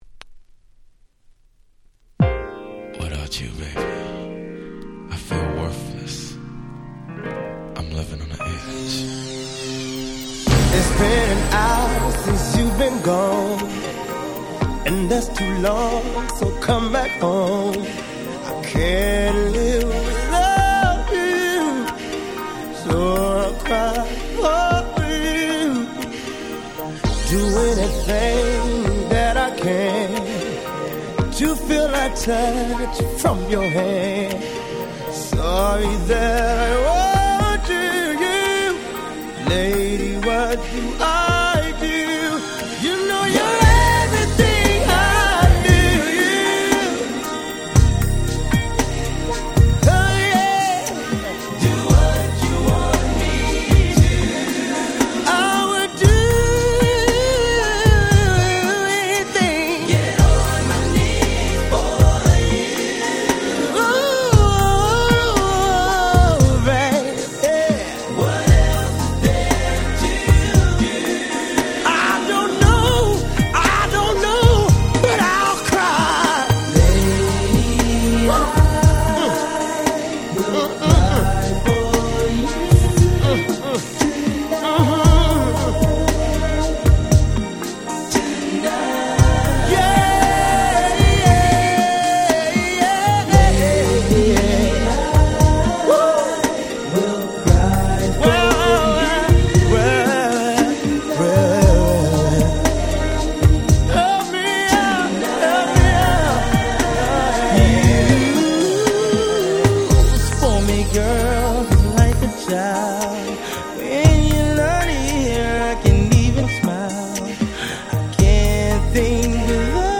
93' Super Hit Slow Jam !!
彼らの真骨頂とも言える甘く切ないSlowバラード。